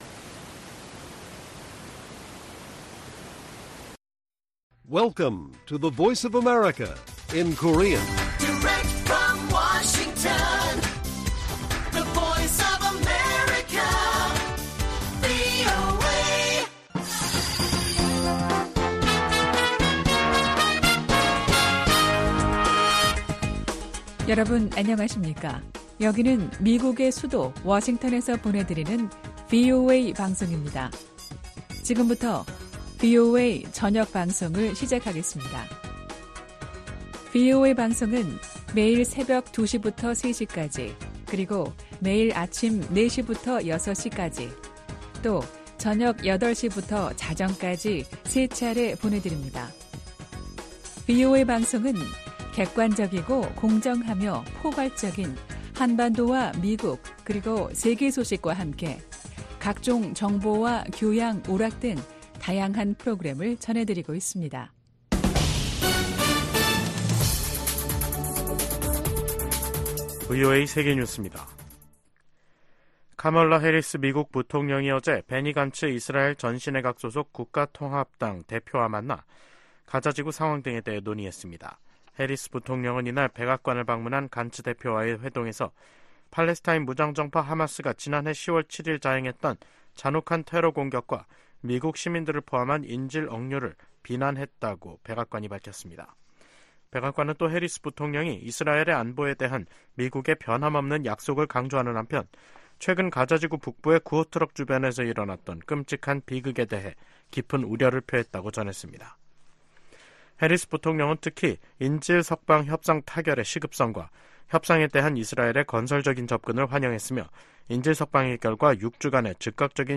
VOA 한국어 간판 뉴스 프로그램 '뉴스 투데이', 2024년 3월 5일 1부 방송입니다. 북한의 영변 경수로 가동 움직임이 계속 포착되고 있다고 국제원자력기구(IAEA)가 밝혔습니다. 북한에서 철수했던 유럽 국가들의 평양 공관 재가동 움직임에 미국 정부가 환영의 뜻을 밝혔습니다. 북한은 4일 시작된 미한 연합훈련 '프리덤실드(FS)'가 전쟁연습이라고 주장하며 응분의 대가를 치를 것이라고 위협했습니다.